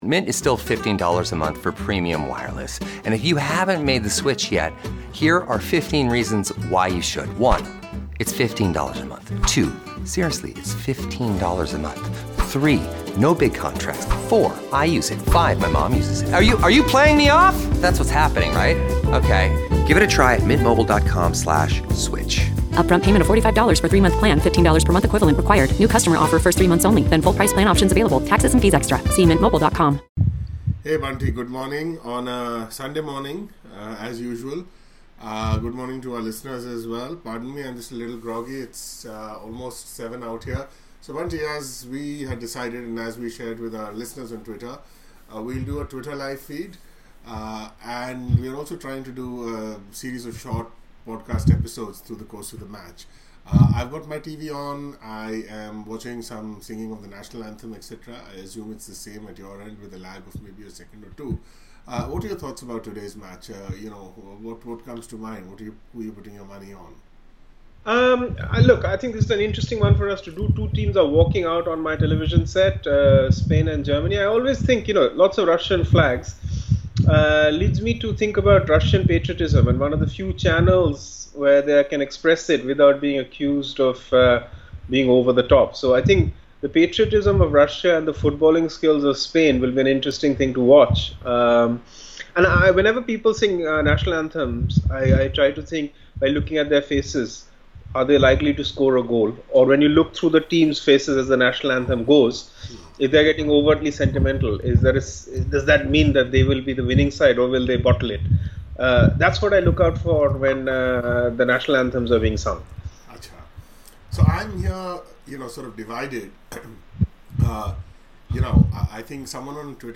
Part I of our parallel podcast commentary on the Spain-Russia match